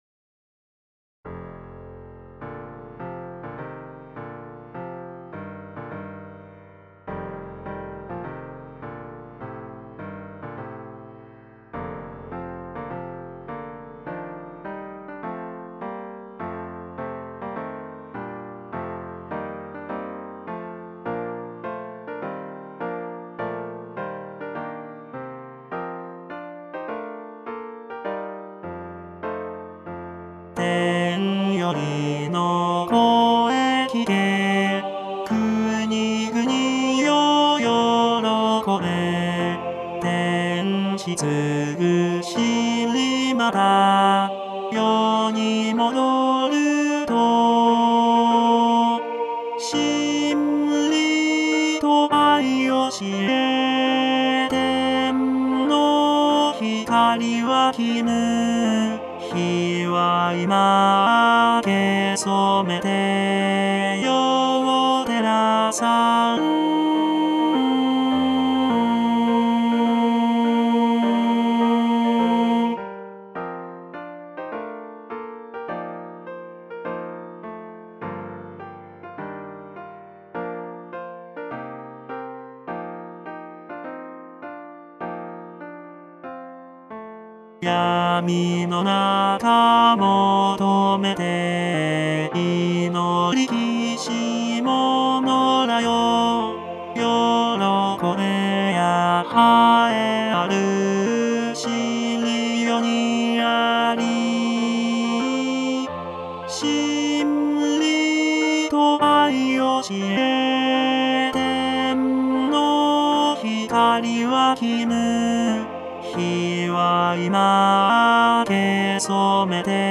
テノール（フレットレスバス音）